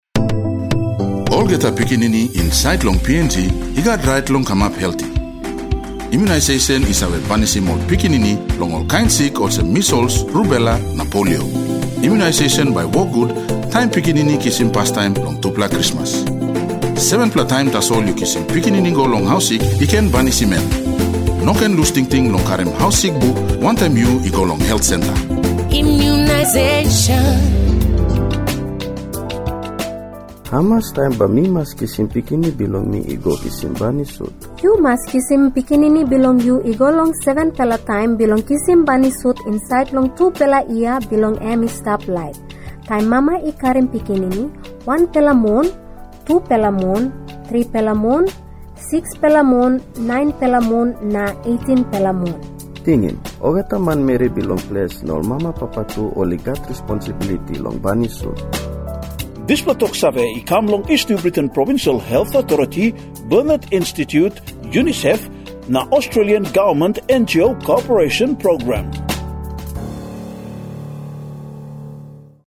NBC Radio East New Britain: immunisation advertisements
nbc-radio-east-new-britain-burnet-immunisation-jingle.mp3